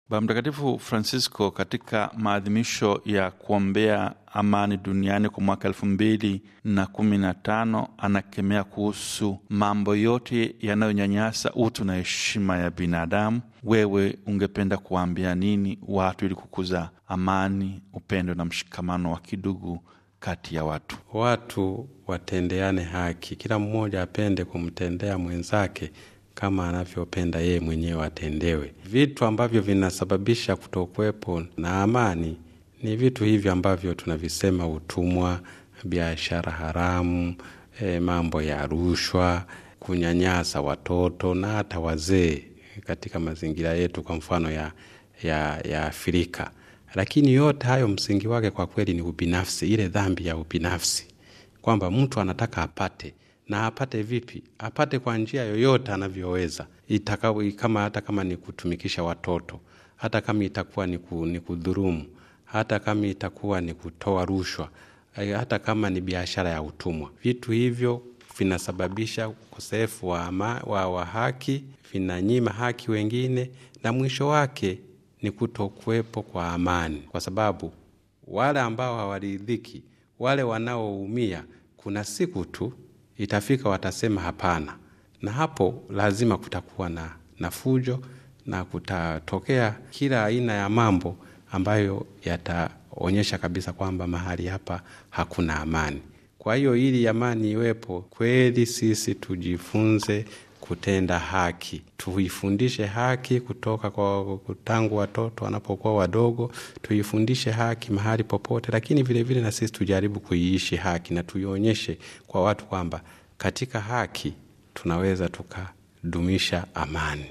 Askofu Renatus Nkwande wa Jimbo Katoliki Bunda, Tanzania, katika mahojiano maalum na Radio Vatican anasema kwamba, ikiwa kama watu wanataka kudumisha amani ya kweli wanapaswa kutendeana haki kama sehemu ya utekelezaji wa kanuni ya dhahabu!